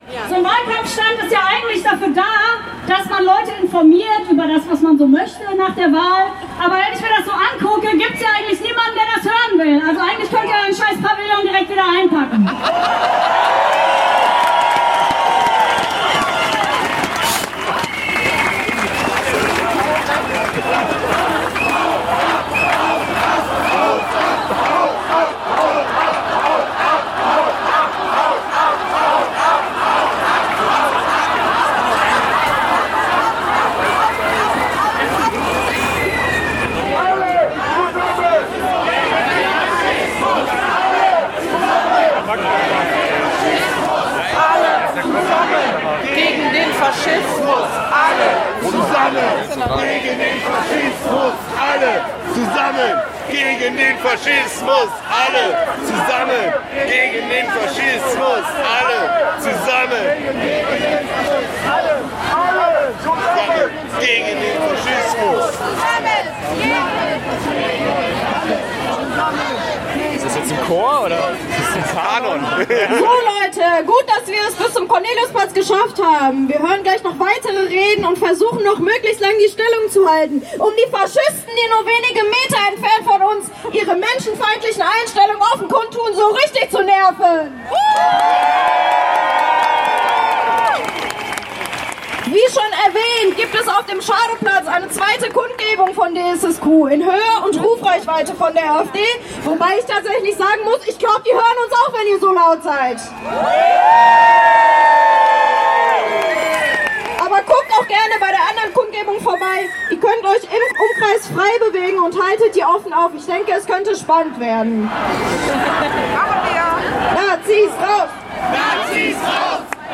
Ankunft bei der Abschlusskundgebung
DSSQ begrüßte die Demonstrierenden (und die AfD) zur Abschlusskundgebung (Audio 7/16) [MP3]